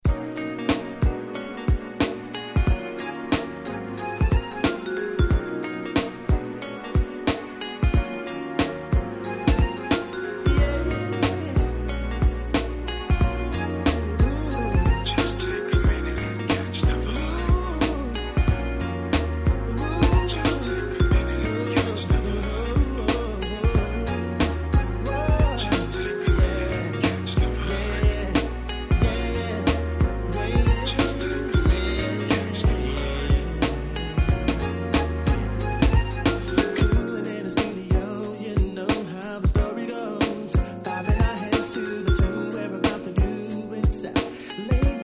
Super Smooth Tune !!!!!
最高にムーディーでSmoothなナンバーに仕上がっております。